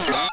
trcamera.wav